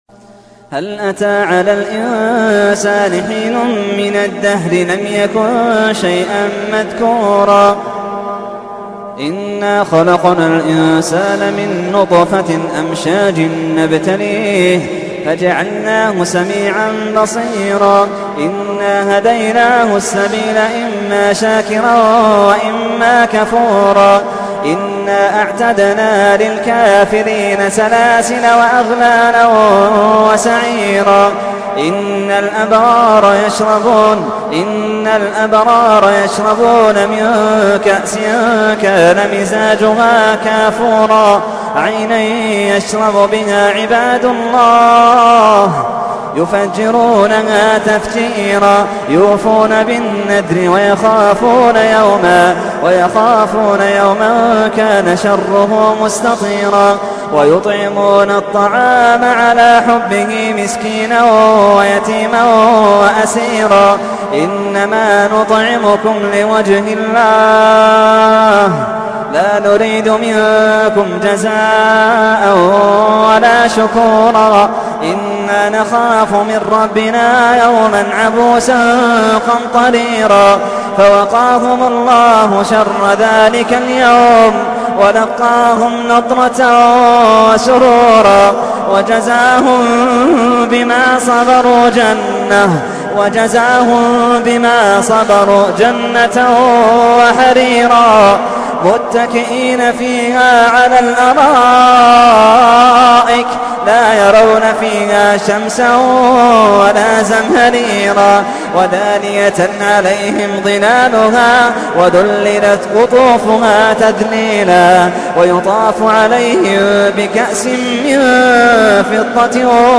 تحميل : 76. سورة الإنسان / القارئ محمد اللحيدان / القرآن الكريم / موقع يا حسين